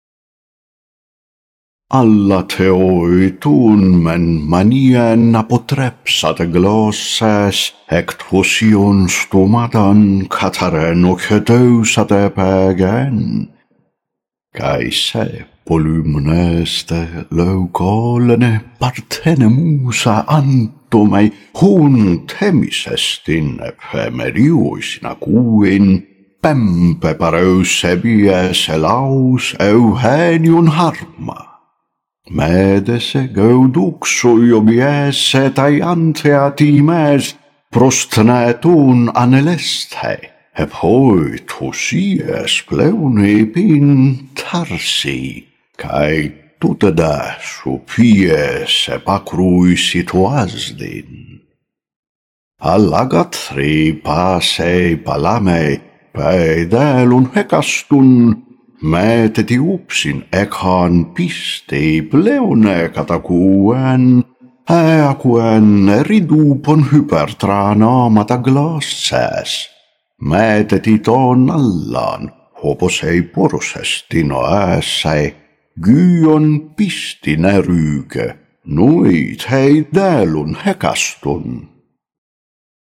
You can follow the original text, recited in reconstructed Ancient Greek pronunciation.